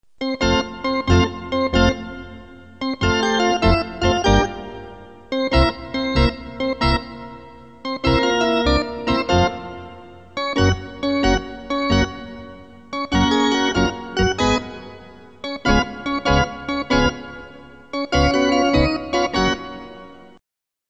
Category: Sports   Right: Personal